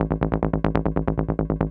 Dance / Bass / SNTHBASS071_DANCE_140_A_SC3.wav